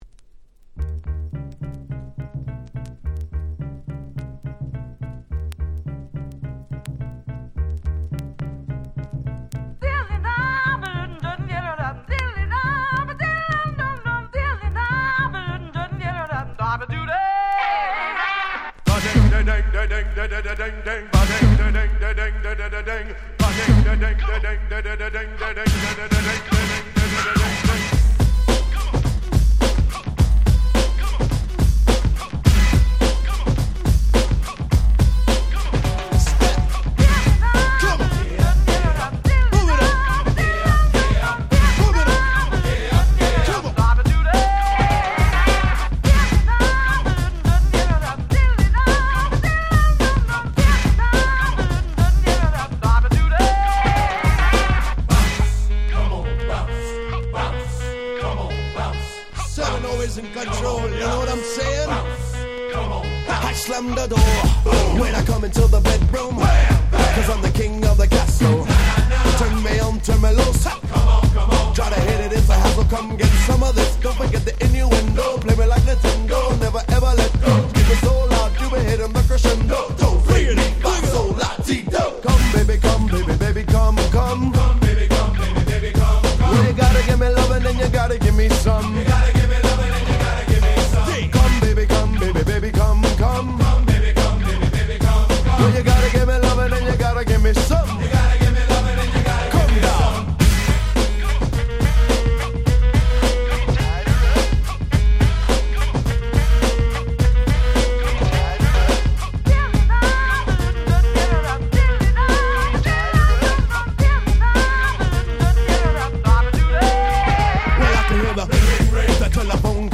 93' Smash Hit Hip Hop !!
ケーセブン パーティーチューン 90's Boom Bap ブーンバップ ニュースクール